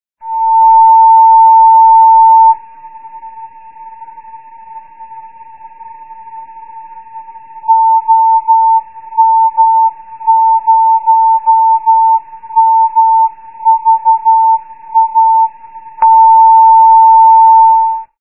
Overview of the Ham Radio Beacons in OK & OM